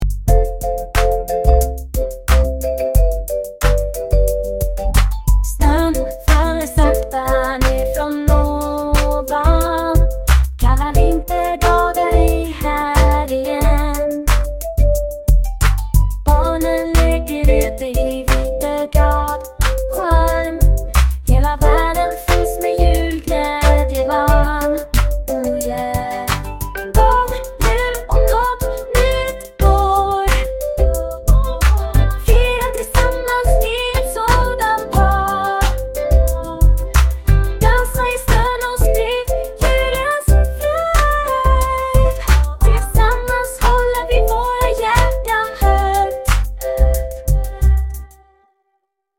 Som en liten julgåva har vi satt ihop en specialinspelning med Ai tomten som skapat en unik julsång.